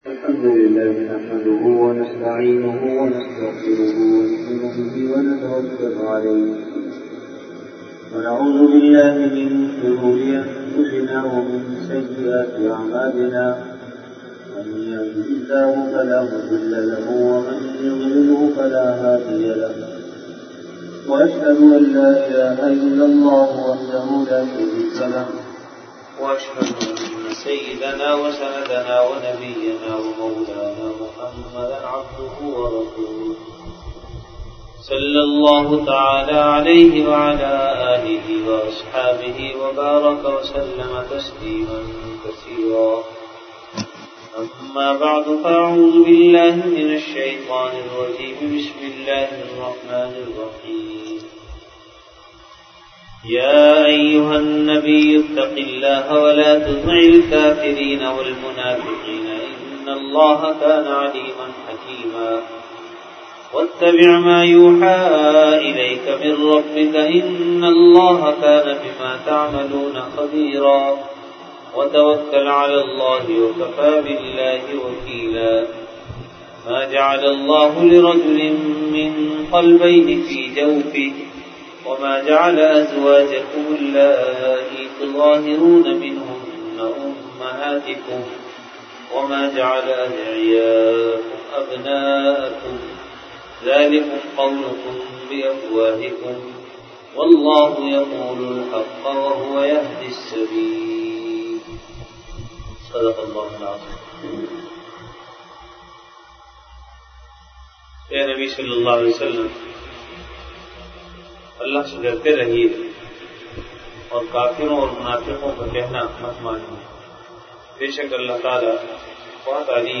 Delivered at Jamia Masjid Bait-ul-Mukkaram, Karachi.
Dars-e-quran · Jamia Masjid Bait-ul-Mukkaram, Karachi